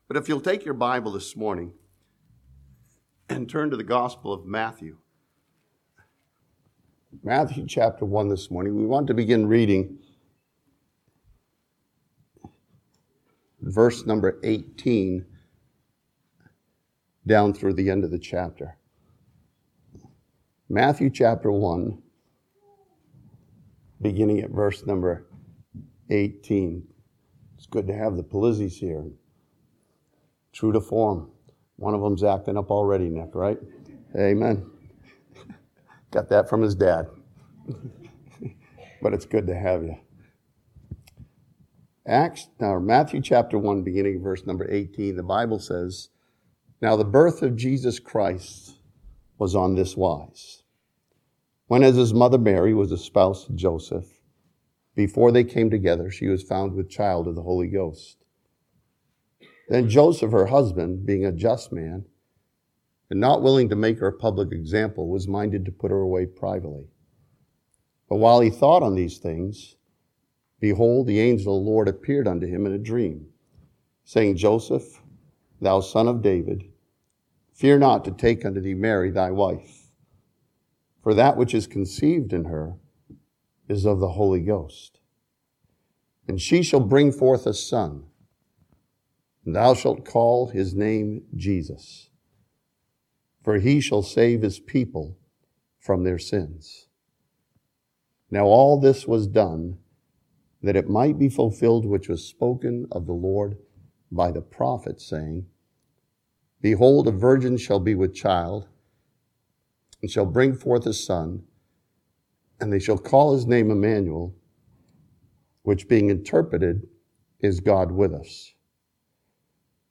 This sermon from Matthew chapter 1 studies the three different births that Christians can experience.